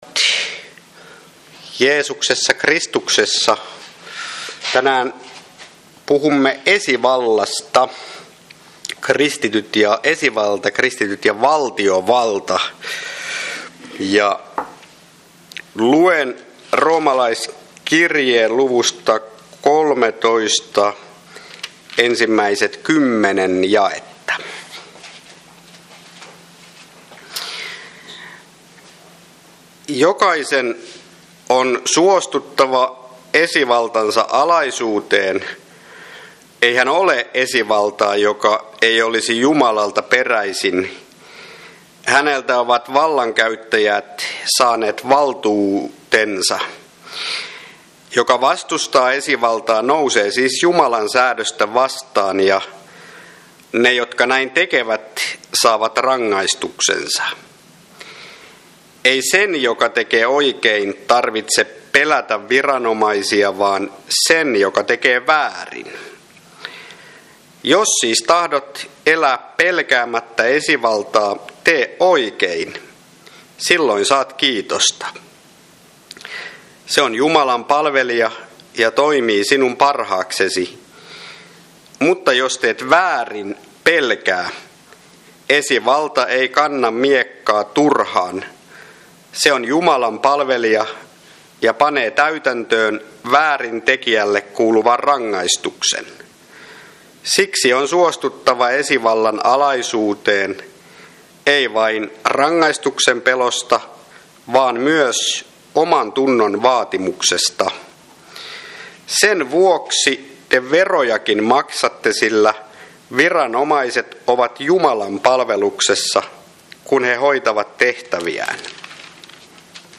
Luento Room. 13
Kokoelmat: Seinäjoen Hyvän Paimenen kappelin saarnat